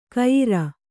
♪ kayira